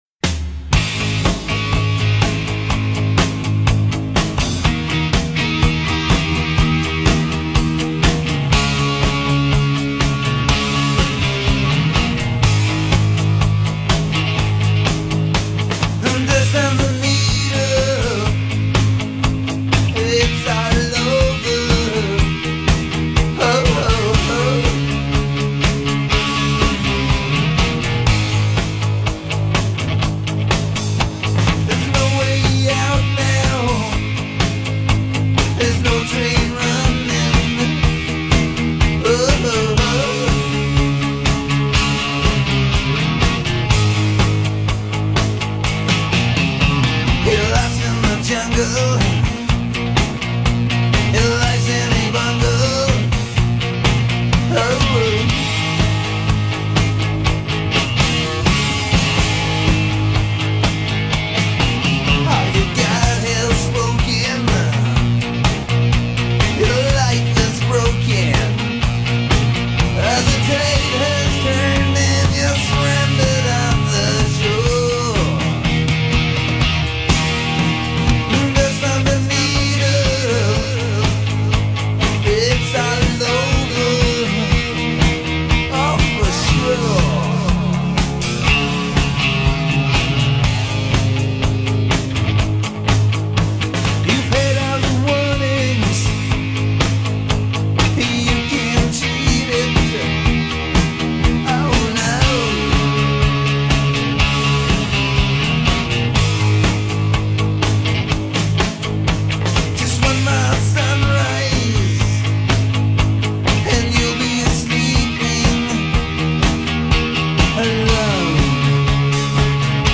Quintessential Pub-Rock. https